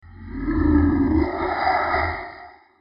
Scary Monster Roar 2 Sound Button - Free Download & Play